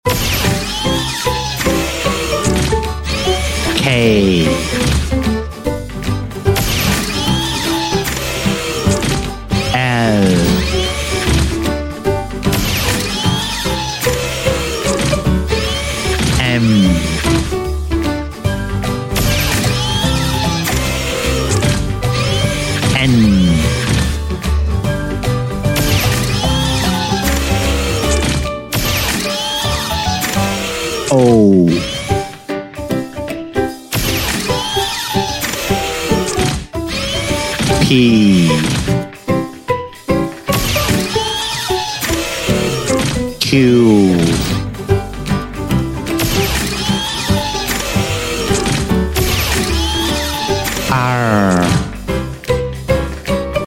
Robot Alphabet (J R) sound effects free download